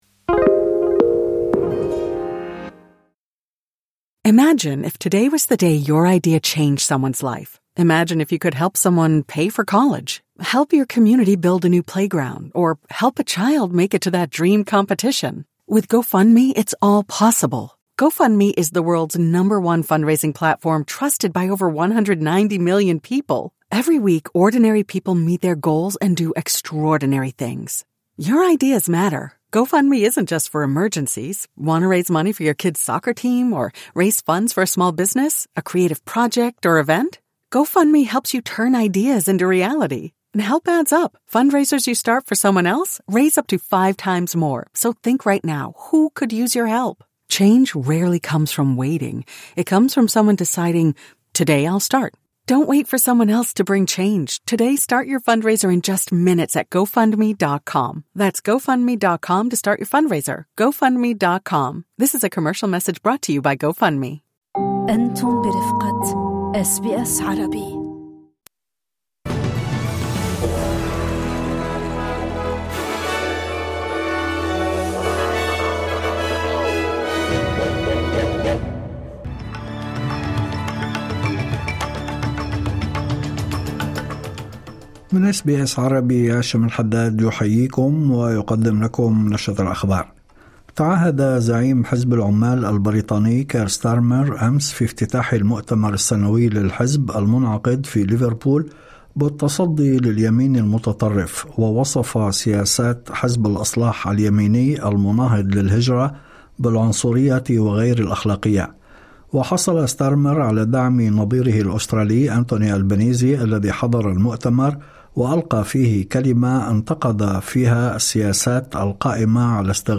نشرة أخبار الظهيرة 29/09/2025